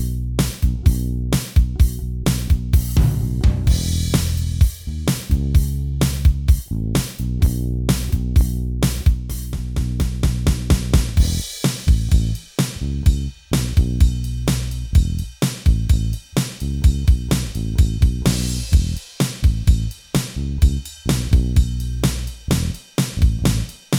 Minus Guitars Pop (1980s) 2:52 Buy £1.50